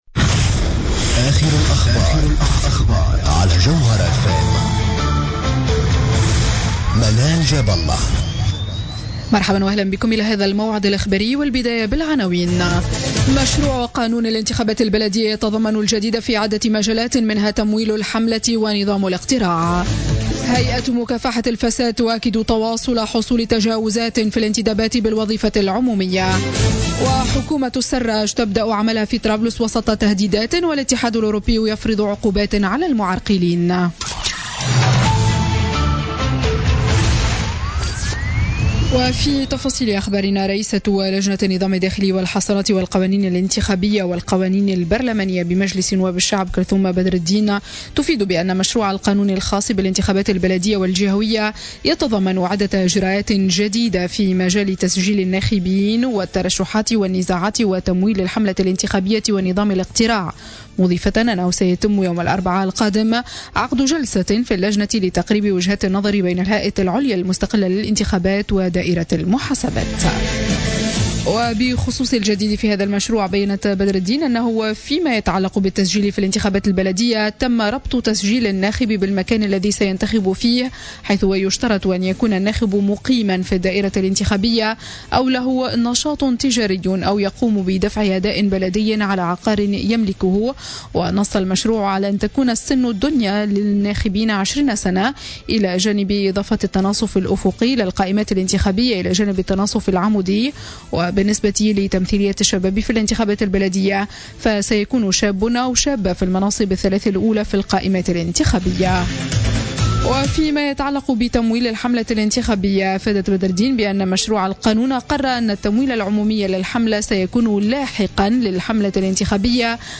نشرة اخبار منتصف الليل ليوم الجمعة غرة أفريل 2016